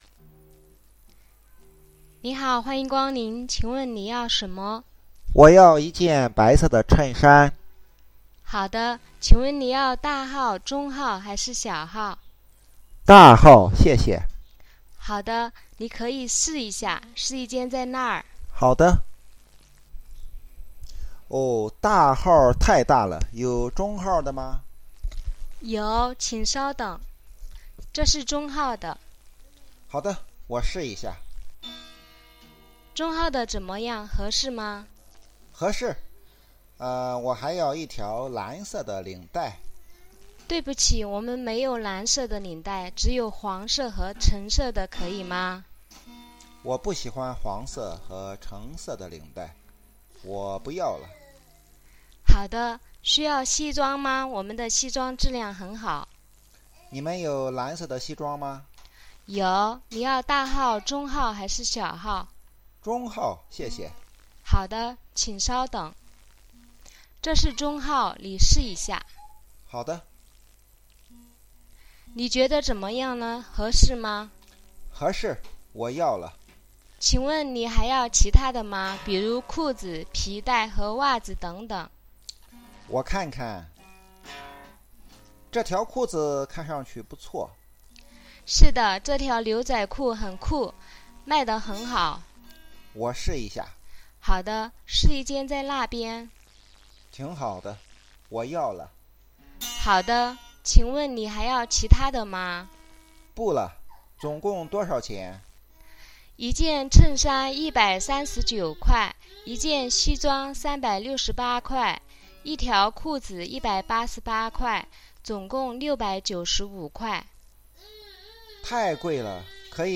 C: Customer;   S: Shop assistant